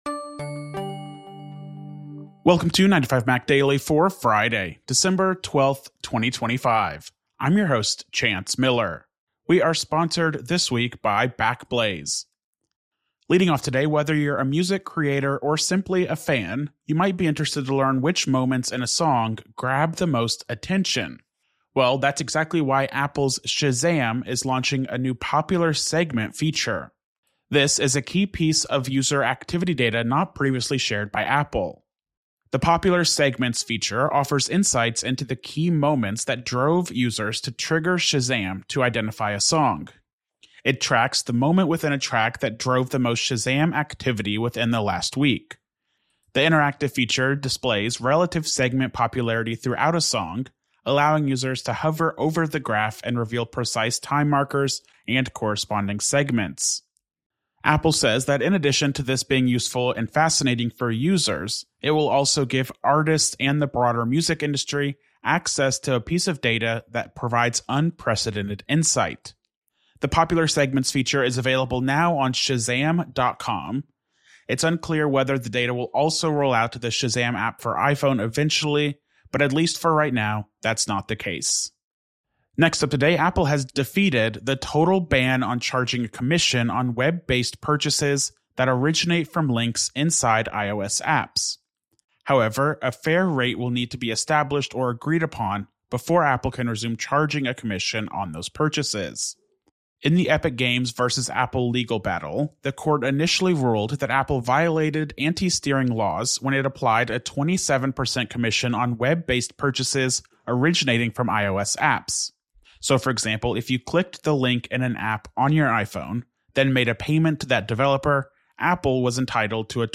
استمع إلى ملخص لأهم أخبار اليوم من 9to5Mac. 9to5Mac يوميا متاح على تطبيق iTunes وApple Podcasts, غرزة, TuneIn, جوجل بلاي، أو من خلال موقعنا تغذية RSS مخصصة لـ Overcast ومشغلات البودكاست الأخرى.